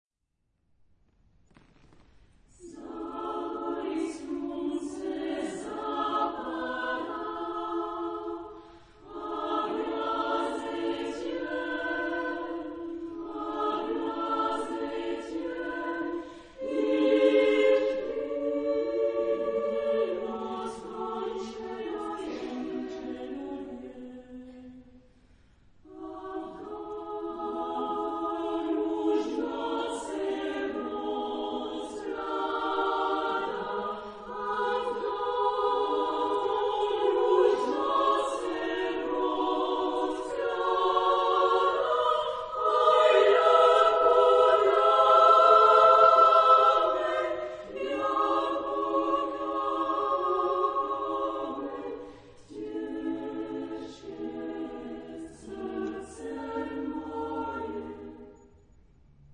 Tri zenske sbory (three choruses for female voices) .- Zapad slunce
Genre-Style-Form: Choir ; Secular ; Romantic
Type of Choir: SSA  (3 women voices )
Discographic ref. : Florilège Vocal de Tours, 2005